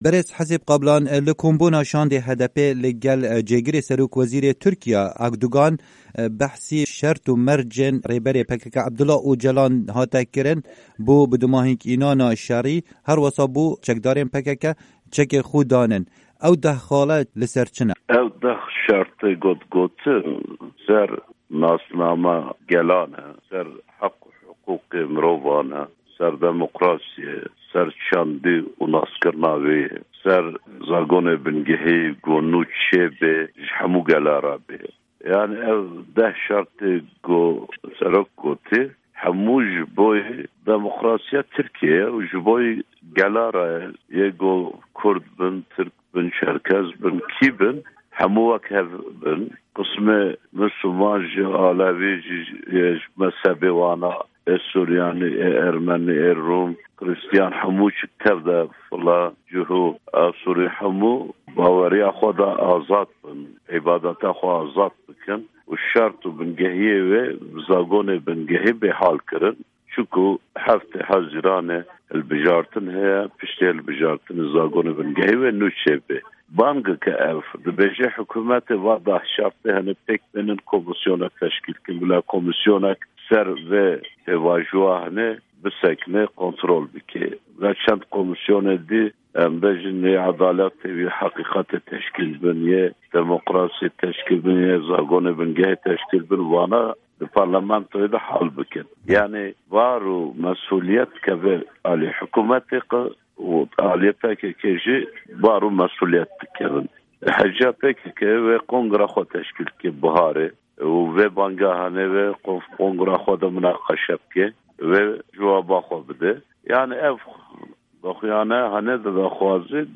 Hevpeyvin digel Hesib Kablan